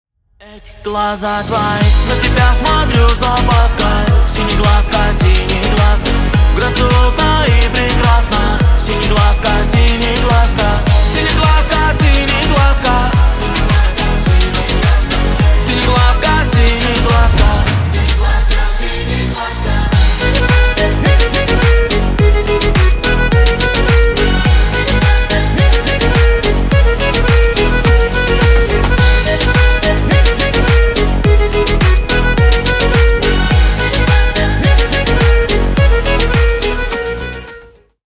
Поп Музыка
кавказские